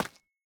Minecraft Version Minecraft Version latest Latest Release | Latest Snapshot latest / assets / minecraft / sounds / block / calcite / step3.ogg Compare With Compare With Latest Release | Latest Snapshot
step3.ogg